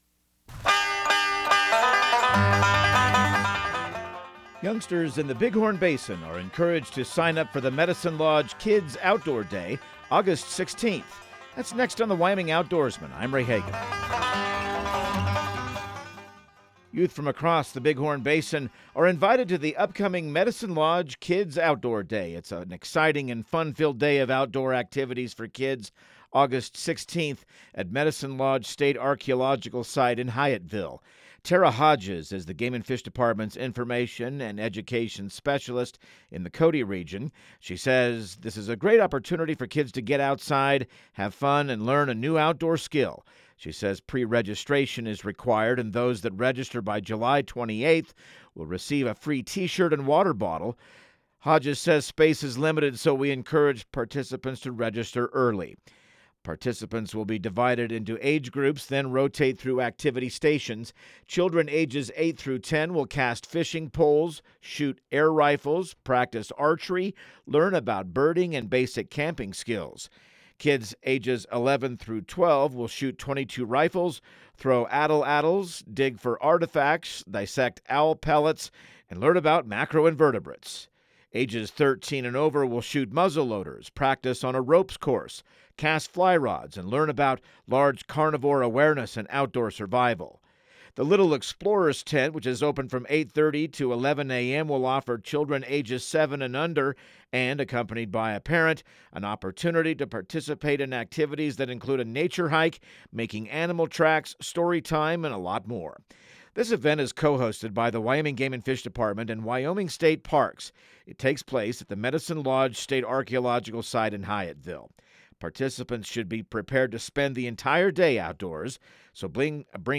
Radio news | Week of July 14